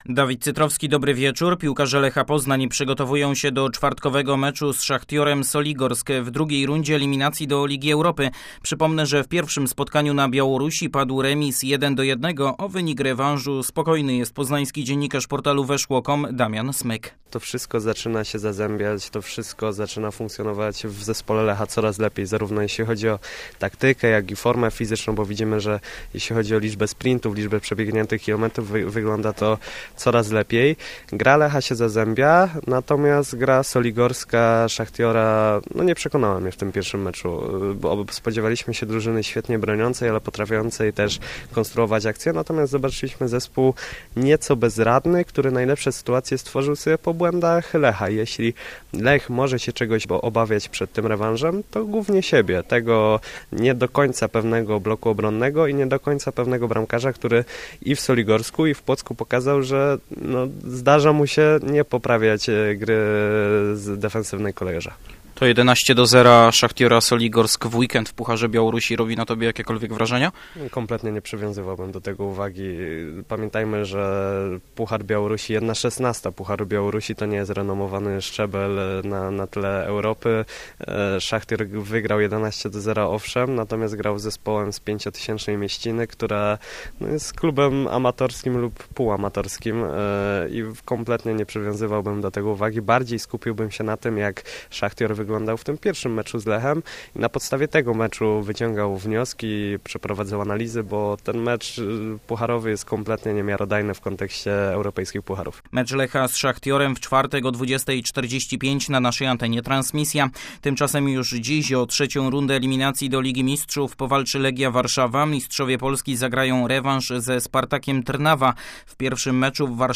31.07 serwis sportowy godz. 19:05